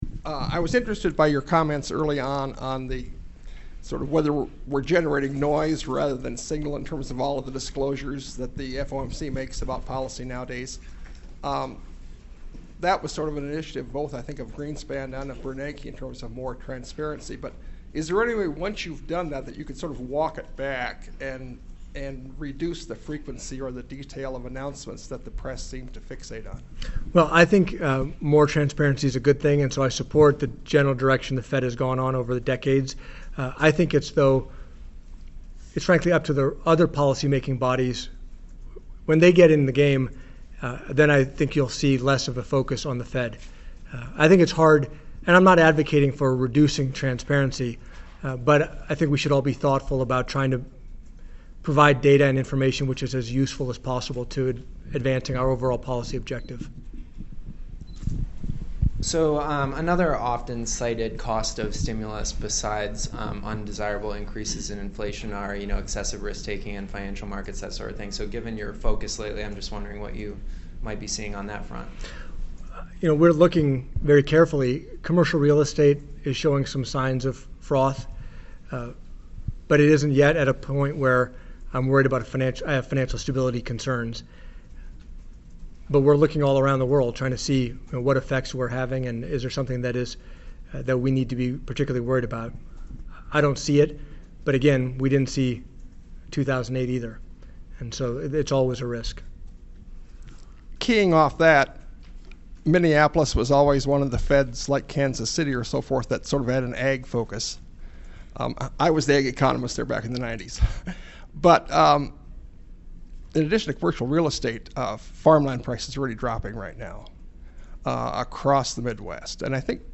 Remarks given at the Economic Club of Minnesota